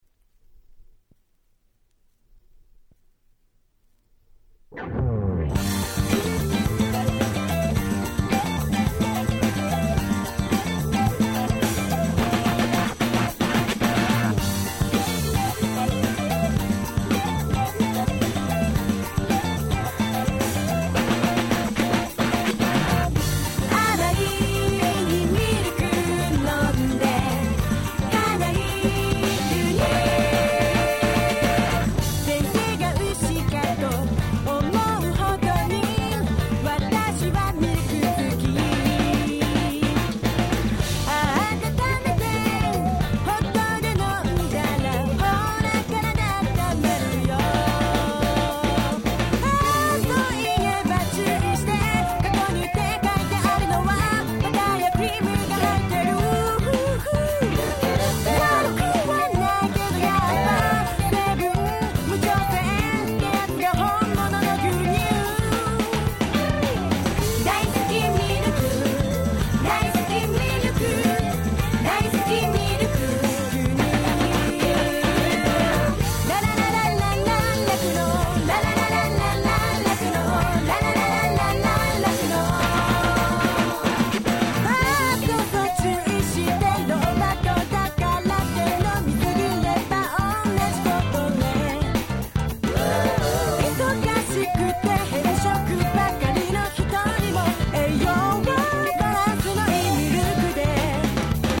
Dance Classicsの往年の名曲達を面白楽しく日本語で替え歌してしまった非常にユーモア溢れるシリーズ！(笑)
Disco ディスコ